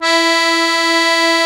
MUSETTE 1 .6.wav